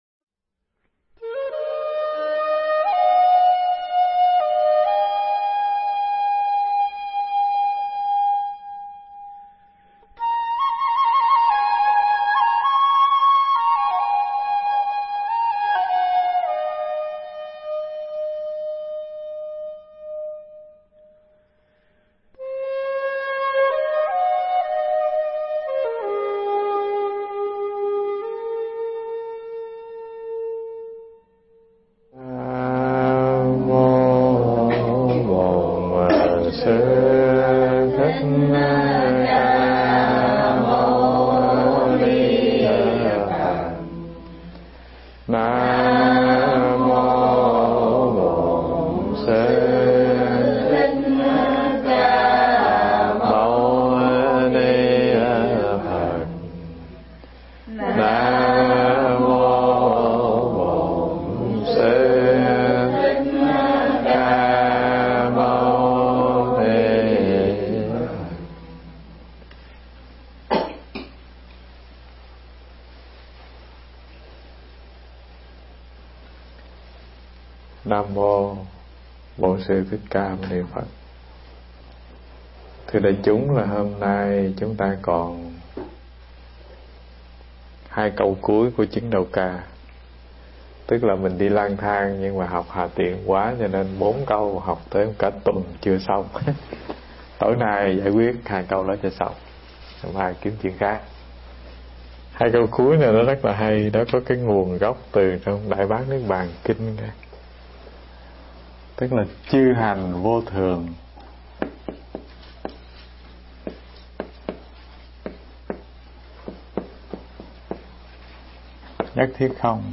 Nghe Mp3 thuyết pháp Từng Hạt Bụi Hiện Chân Thân Phần 5